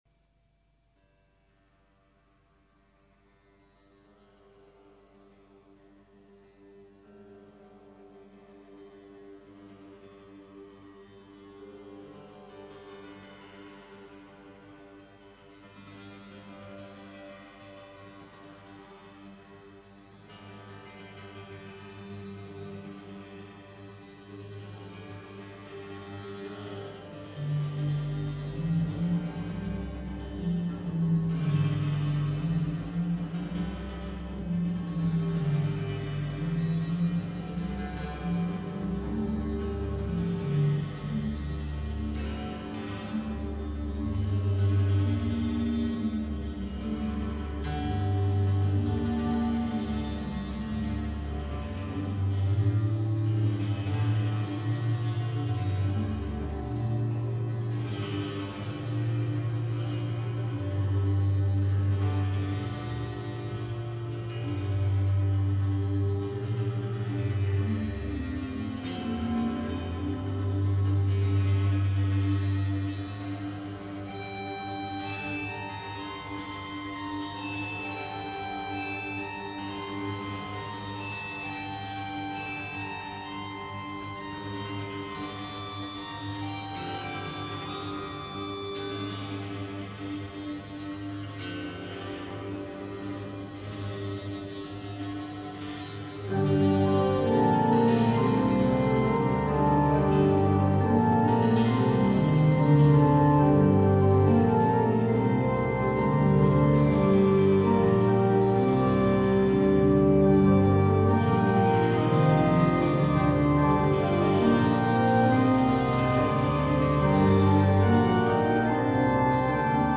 Besetzung: Kirchenorgel und Monochord